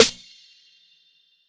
ZV_SNR.wav